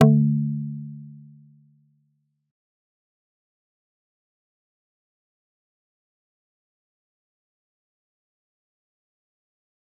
G_Kalimba-D3-mf.wav